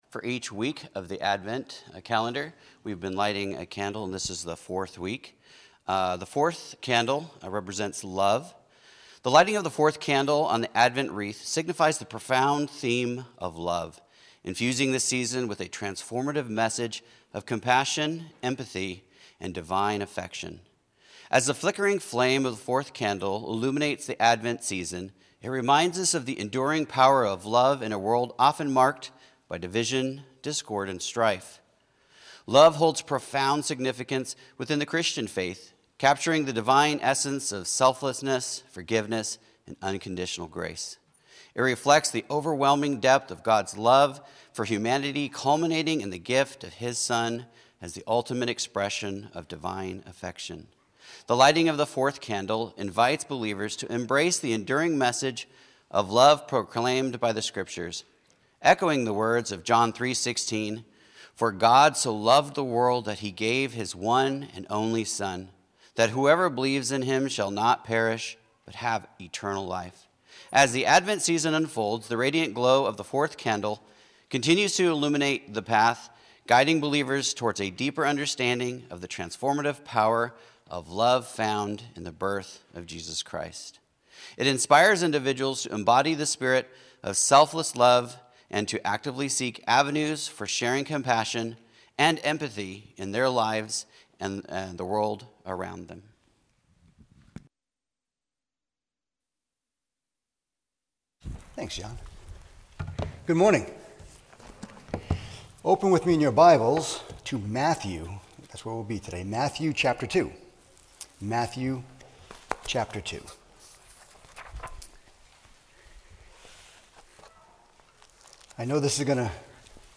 Sermons | Mercer Baptist